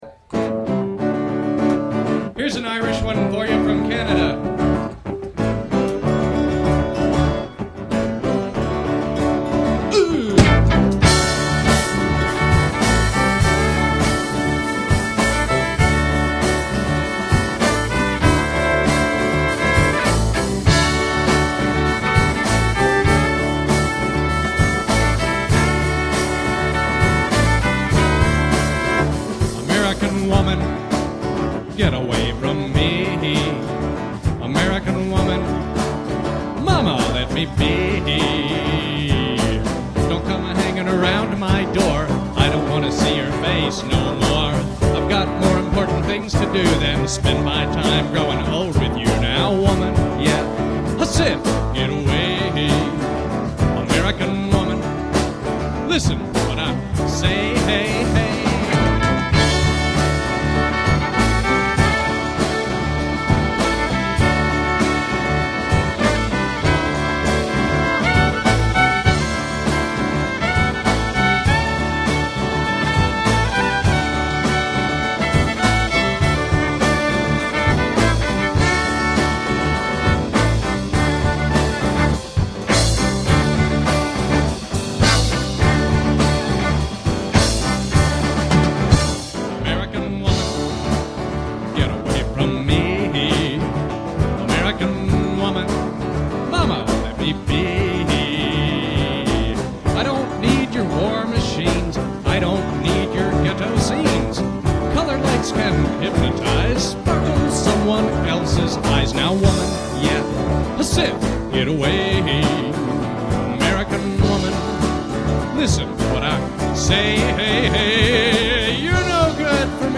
drums
hammer dulcimer